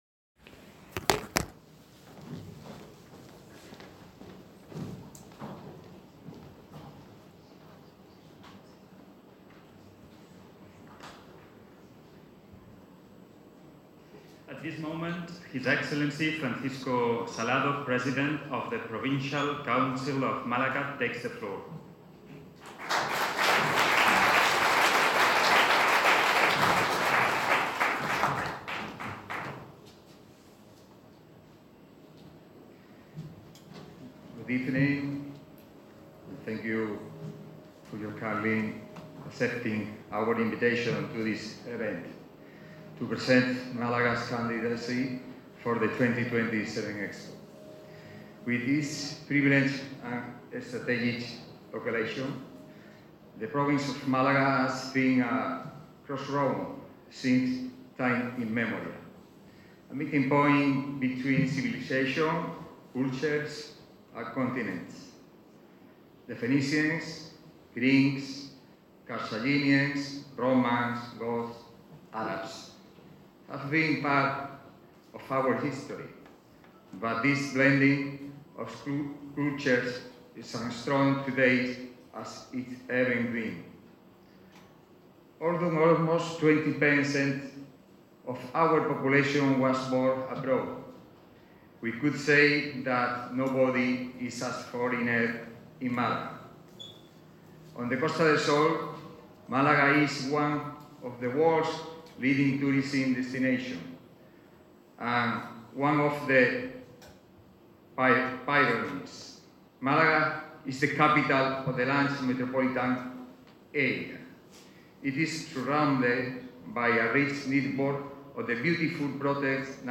El presidente de la Diputación participa en Dubái en la presentación de la candidatura de Málaga para la exposición 'La era urbana: hacia la ciudad sostenible'
intervencion-francisco-salado-en-ingles-dubai.mp3